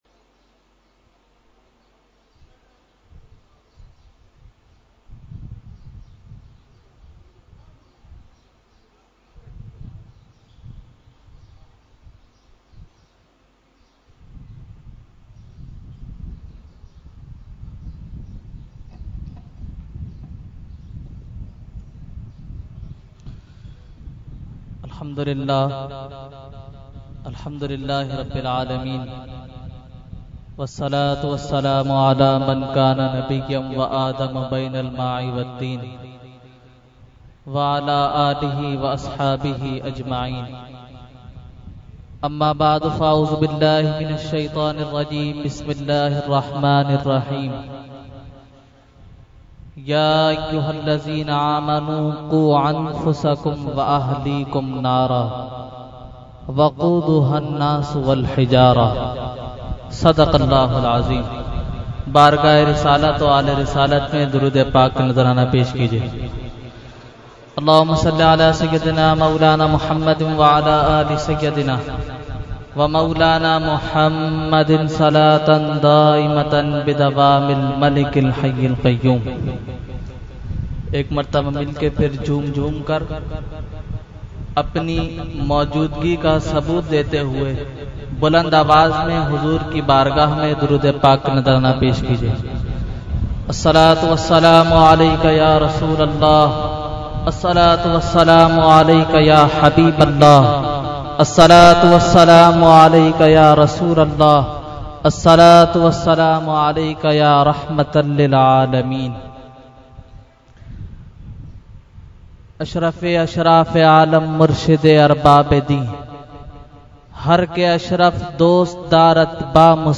Category : Speech | Language : UrduEvent : Shab e Meraj 2016